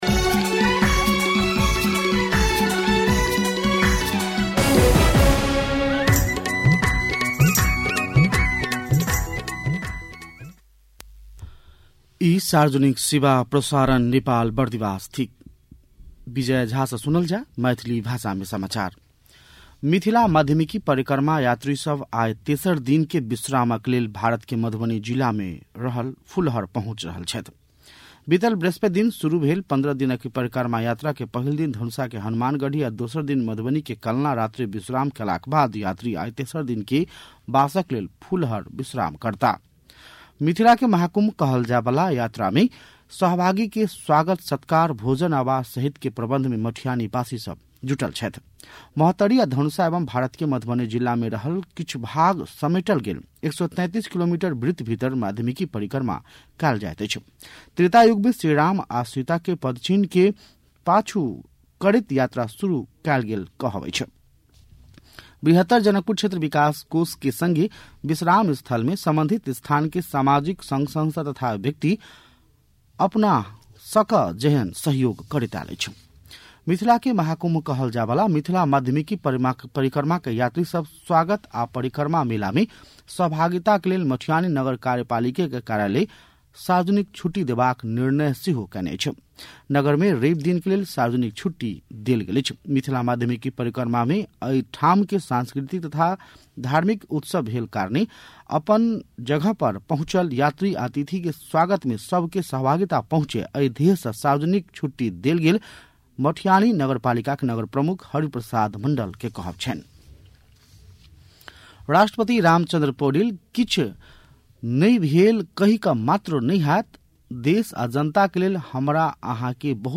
मैथिली भाषामा समाचार : १८ फागुन , २०८१